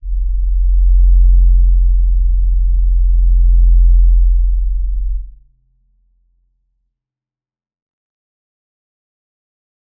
G_Crystal-F1-pp.wav